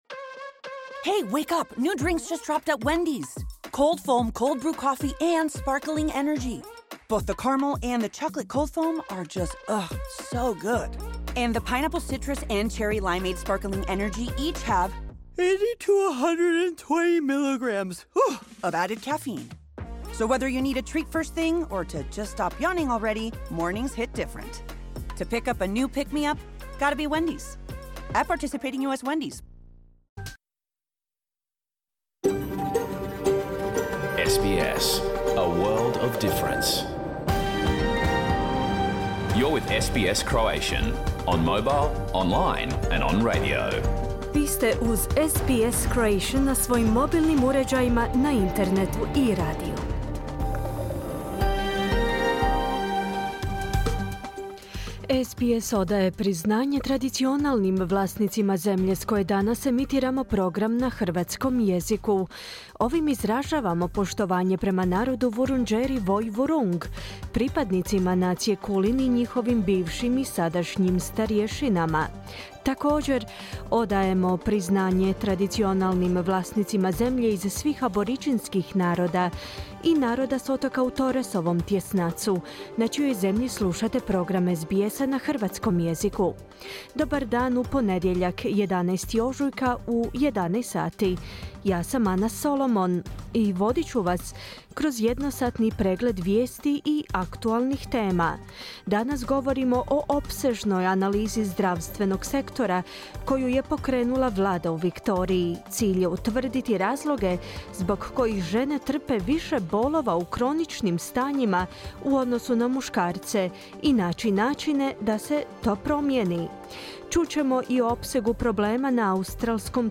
Pregled vijesti i aktualnih tema iz Australije, Hrvatske i ostatka svijeta za pondjeljak, 11. ožujka 2024.. Program je emitiran uživo na radiju SBS, u terminu od 11 do 12 sati, po istočnoaustralskom vremenu.